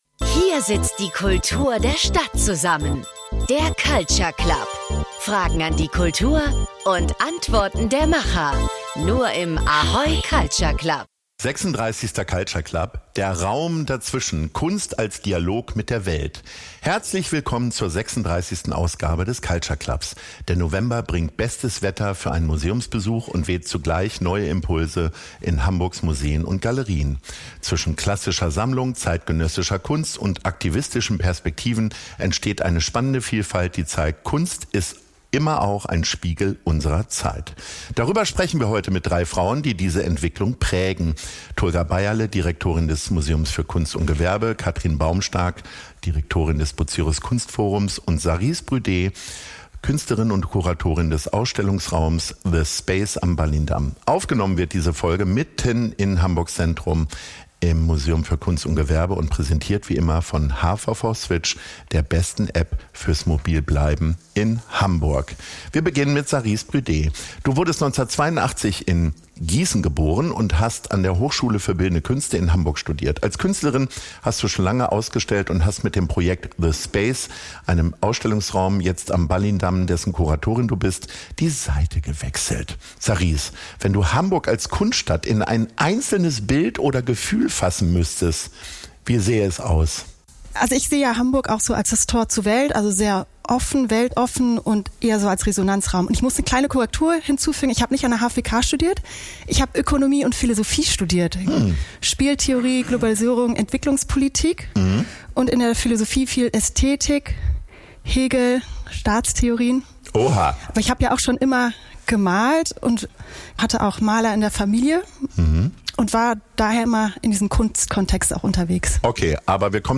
Aufgenommen wurde diese Folge mitten in Hamburgs Zentrum, im Museum für Kunst & Gewerbe, und präsentiert wie immer von HVV Switch, der besten App fürs Mobilbleiben in Hamburg.